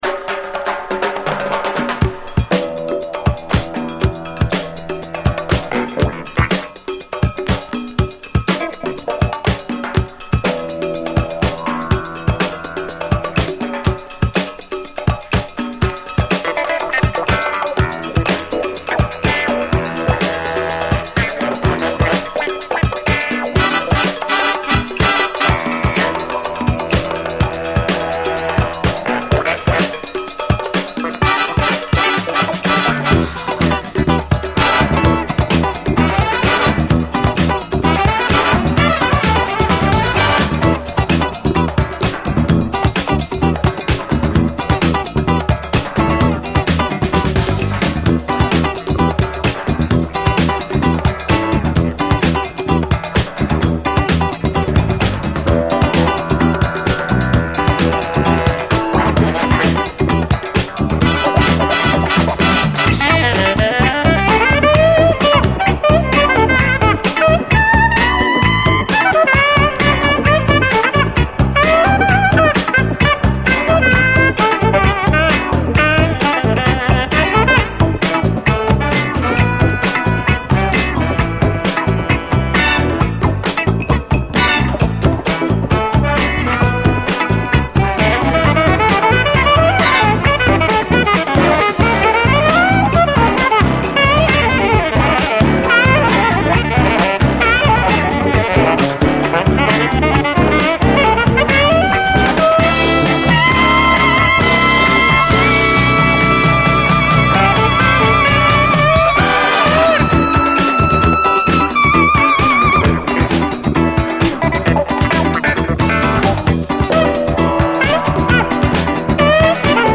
SOUL, 70's～ SOUL, 7INCH
燃えるしかないレアグルーヴ〜オールドスクールB-BOYブレイク・クラシック
めちゃくちゃカッコいいスリリング・レアグルーヴ・トラック！ピースフルで鬼メロウな70’Sソウル・クラシック